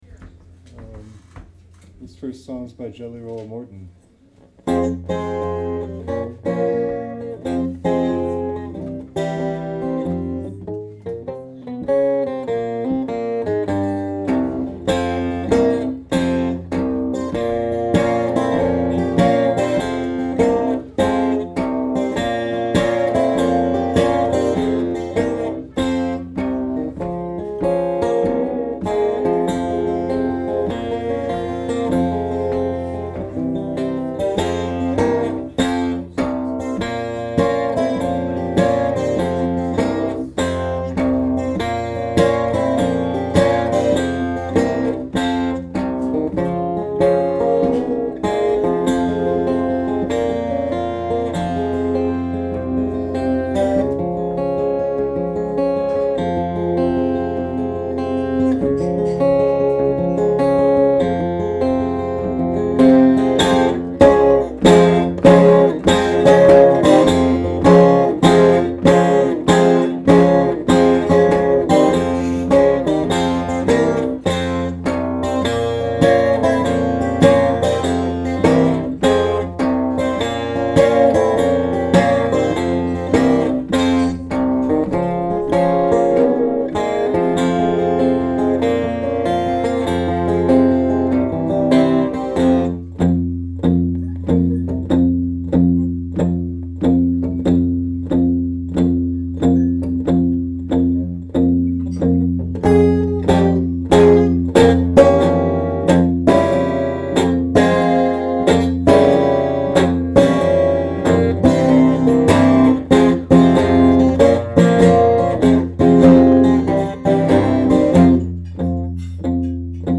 Live performances.